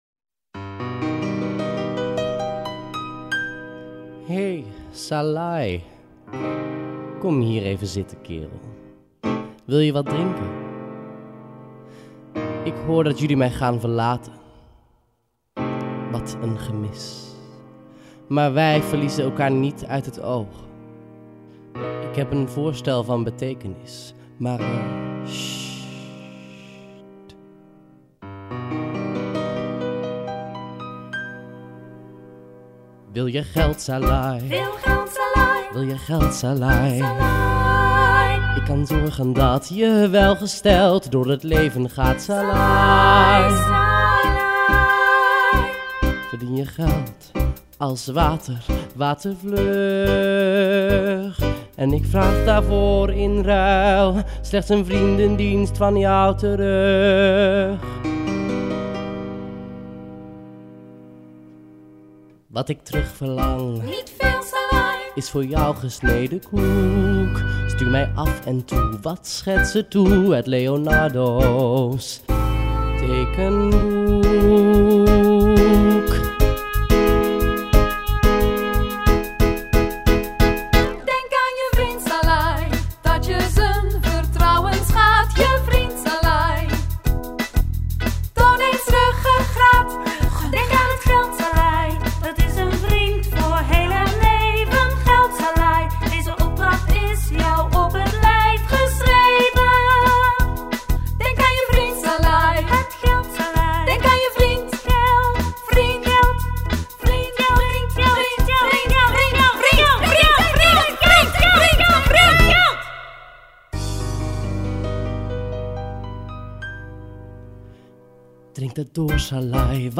Uit “Da Vinci” (musical)